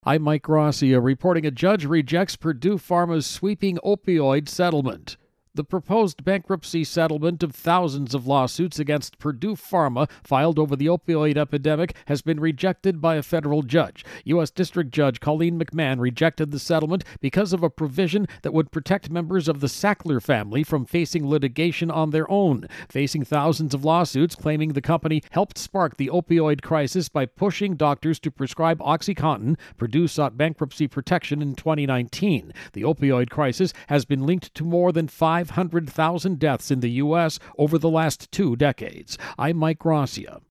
Opioid Crisis-Purdue-Bankruptcy intro and voicer.